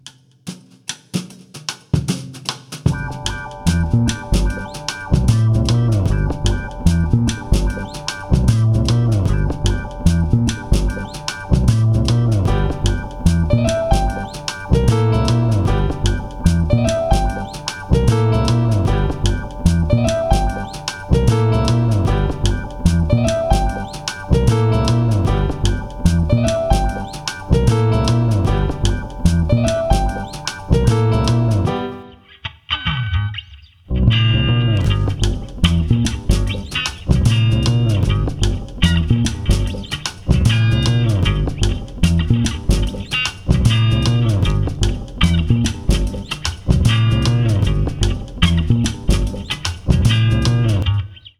(0:51) Some acidjazzy mushroom